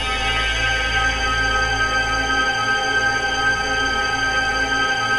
ATMO PAD 25
ATMOPAD25 -LR.wav